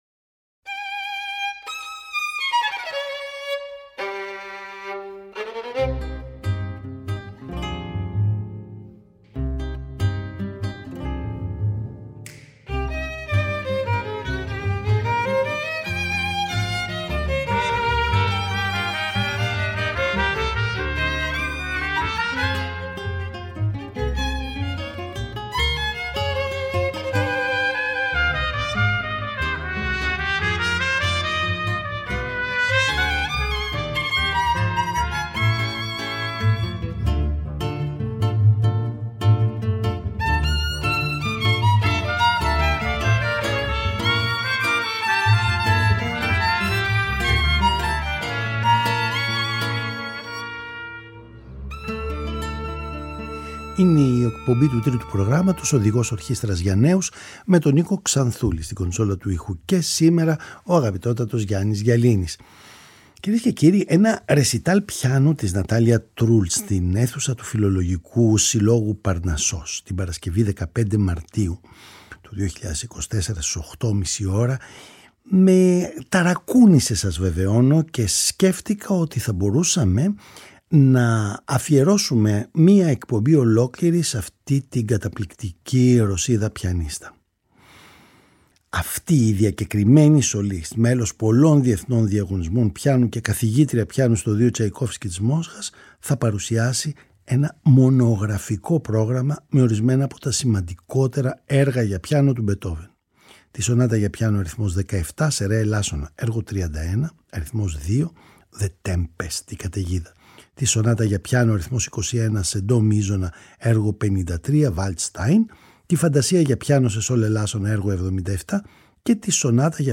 ρεσιταλ πιανου